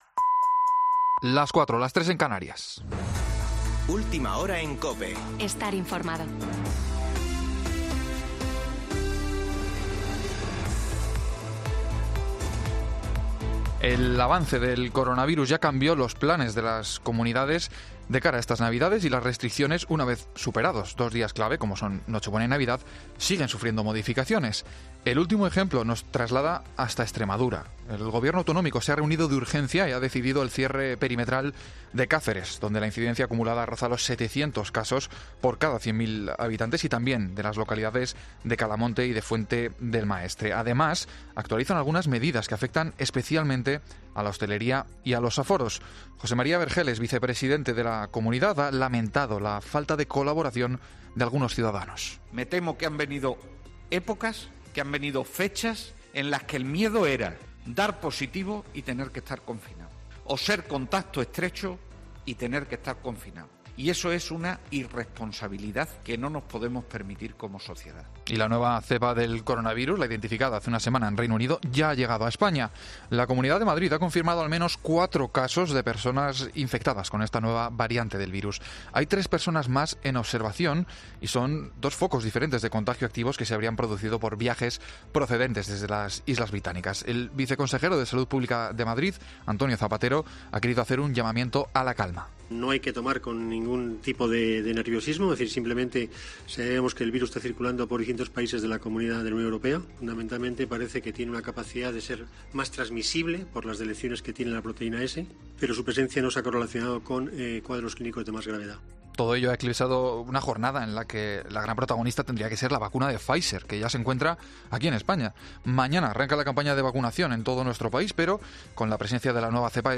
Boletín de noticias de COPE del 26 de diciembre de 2020 a las 16.00 horas